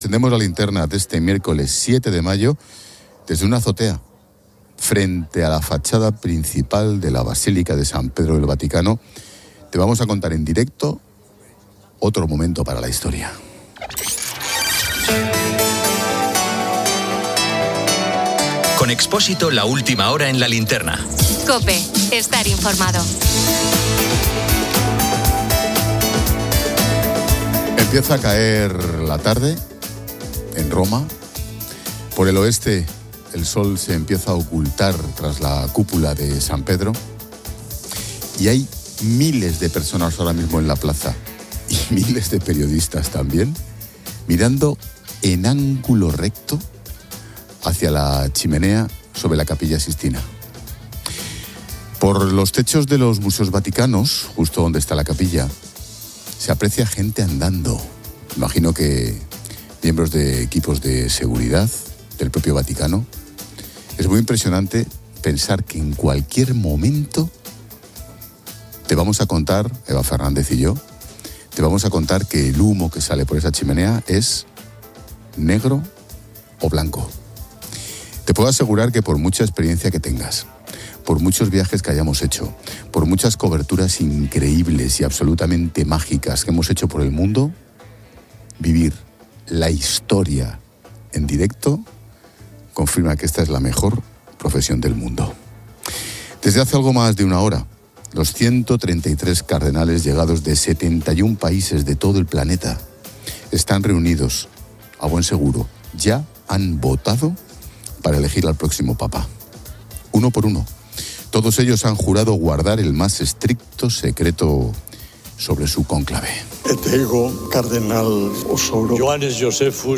La Linterna 19:00H | 07 MAY 2025 | La Linterna Atendemos a la linterna de este miércoles 7 de mayo, desde una azotea frente a la fachada principal de la basílica de San Pedro del Vaticano. Te vamos a contar en directo otro momento para la historia.